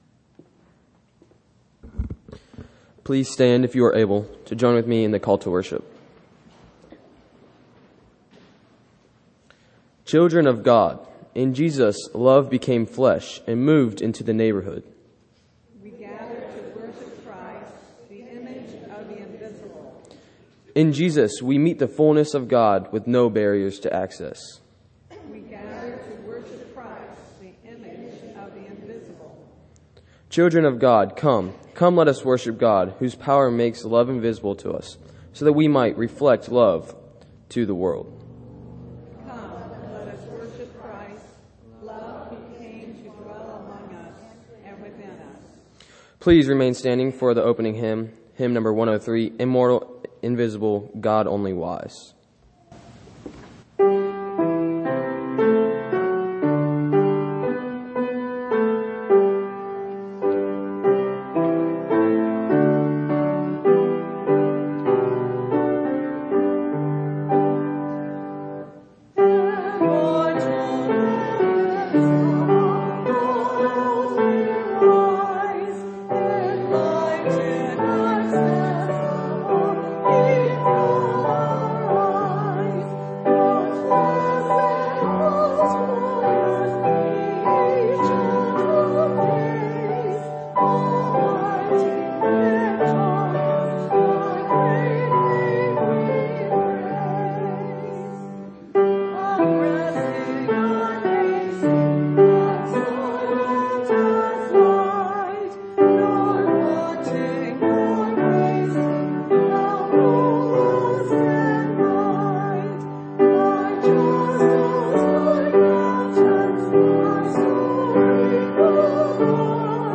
Sermons Archive - Faith Community United Methodist Church - Xenia, OH